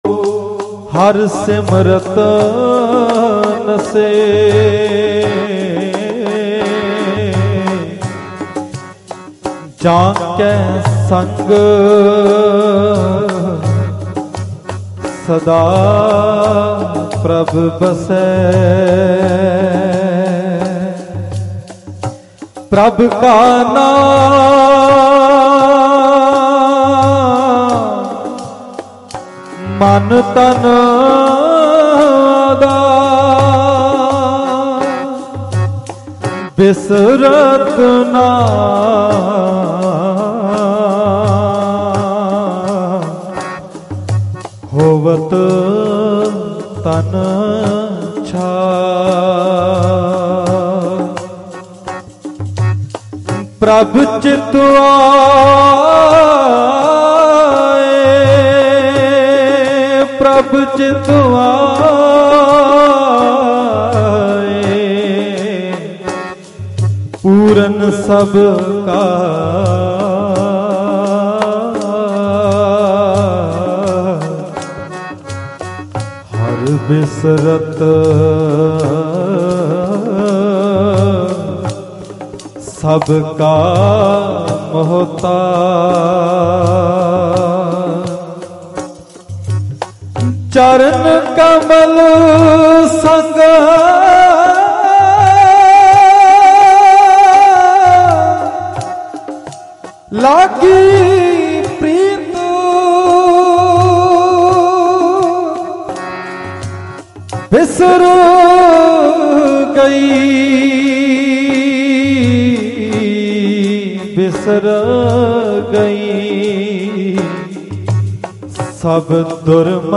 Mp3 Diwan Audio by Bhai Ranjit Singh Ji Khalsa Dhadrianwale at Parmeshardwar
Live_Gurmat_Samagam_Rajgarh_Patiala_5_April_2025_Dhadrianwale.mp3